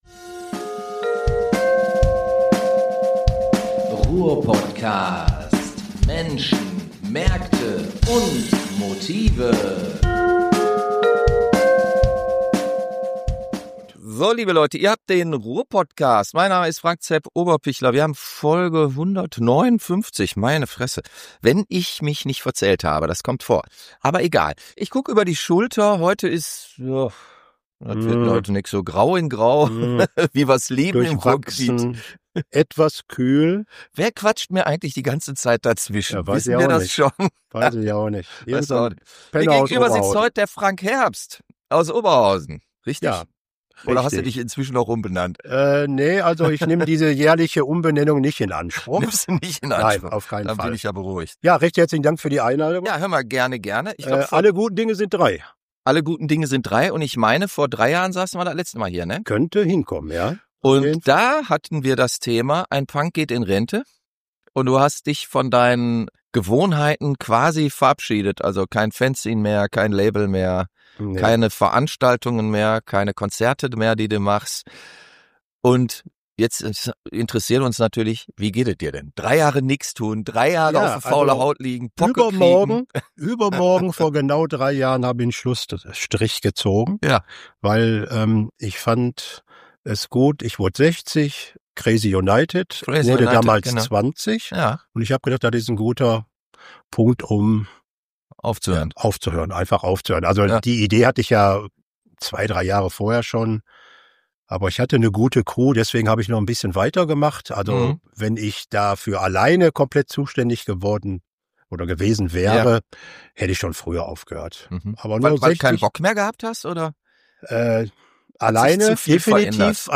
Warnhinweis: Hier wird "frei Schnauze" nach Ruhrgebietsart gesprochen.